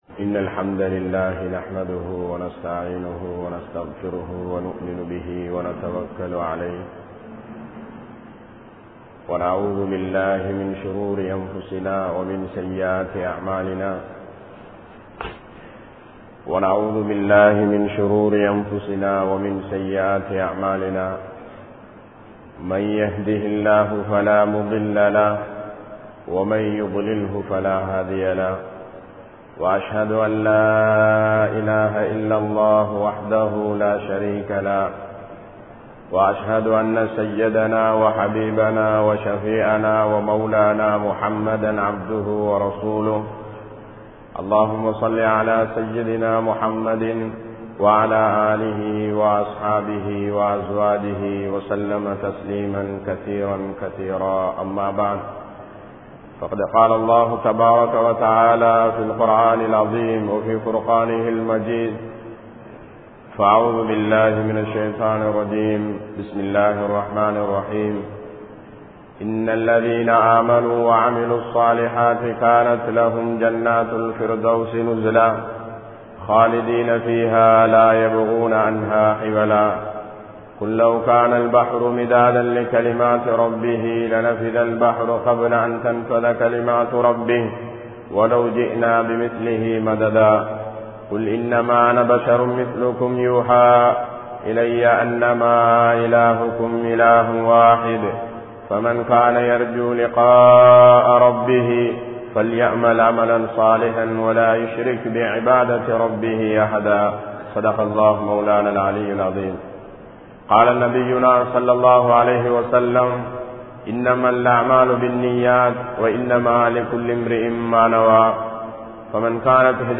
Namathu Vaalvin Nokkam Enna?(நமது வாழ்வின் நோக்கம் என்ன?) | Audio Bayans | All Ceylon Muslim Youth Community | Addalaichenai
Grand Jumua Masjitth